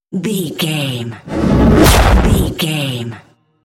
Whoosh to hit trailer
Sound Effects
Atonal
intense
tension
the trailer effect